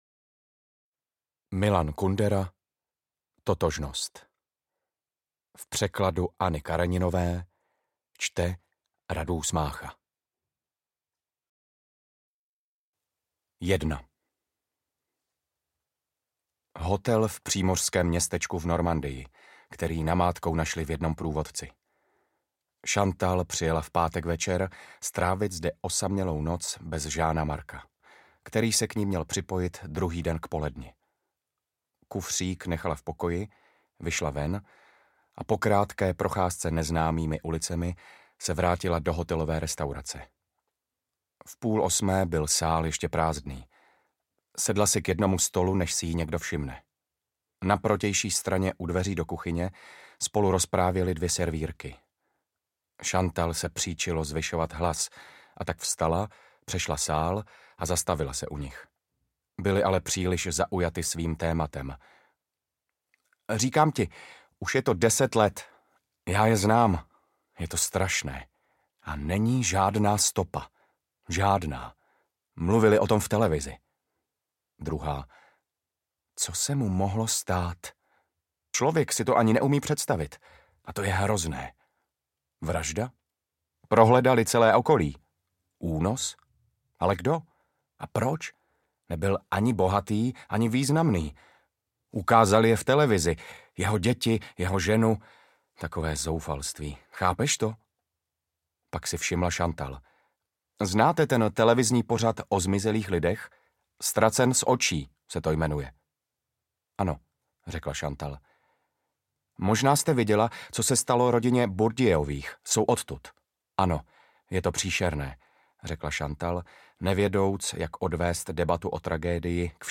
Interpret:  Radúz Mácha